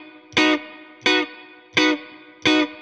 DD_StratChop_85-Bmaj.wav